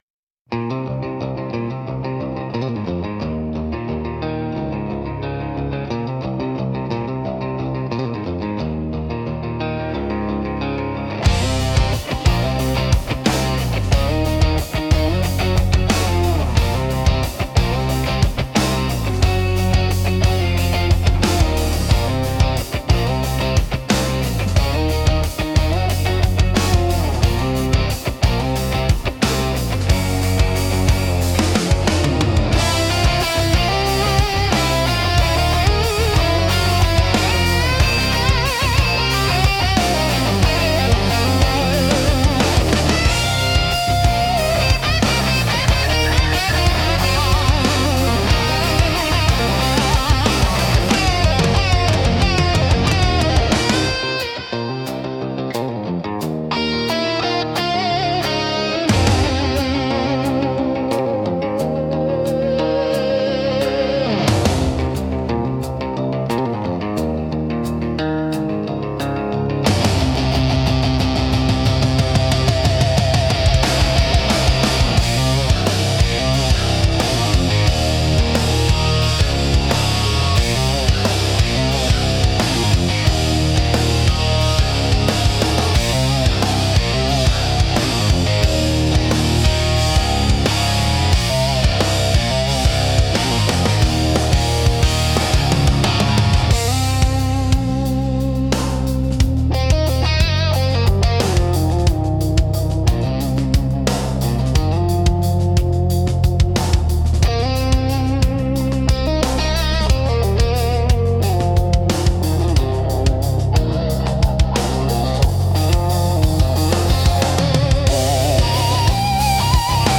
Instrumental - The Pull of the Next Town 3.24